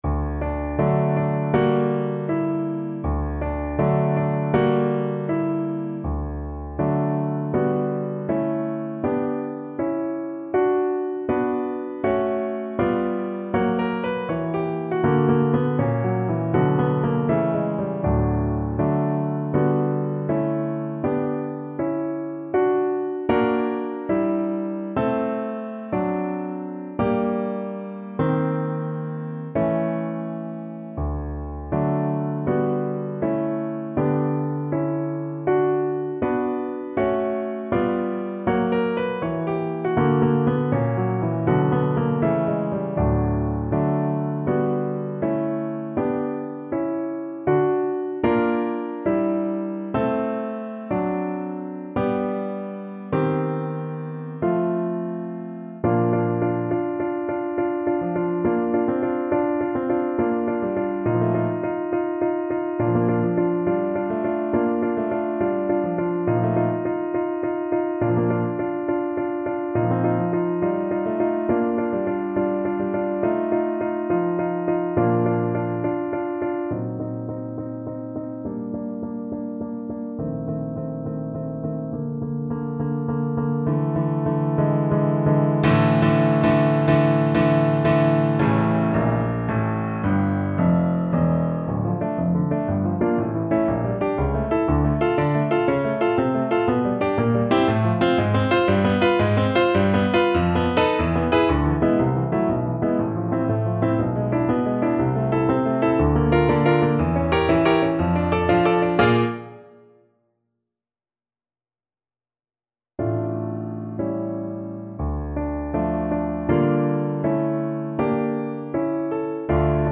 2/4 (View more 2/4 Music)
Moderato =80
Classical (View more Classical Cello Music)